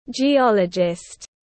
Nhà địa chất học tiếng anh gọi là geologist, phiên âm tiếng anh đọc là /dʒiˈɑːlədʒɪst/.
Geologist /dʒiˈɑːlədʒɪst/